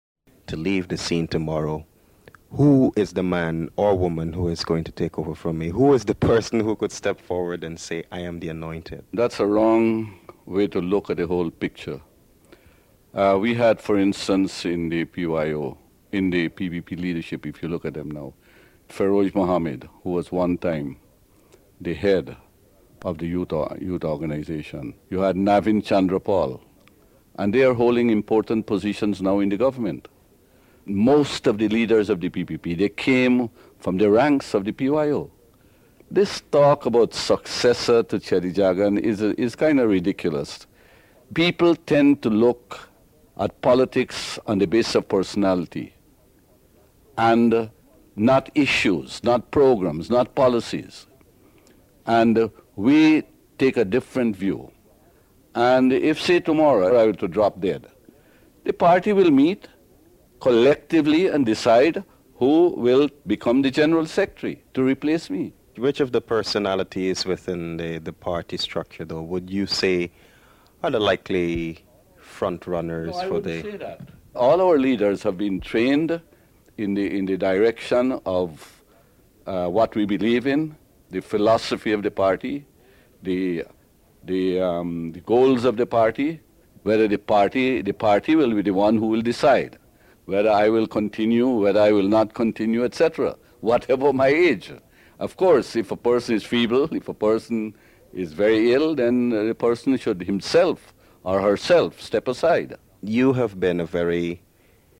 An interview with Dr. Jagan before falling ill (02:48-05:36)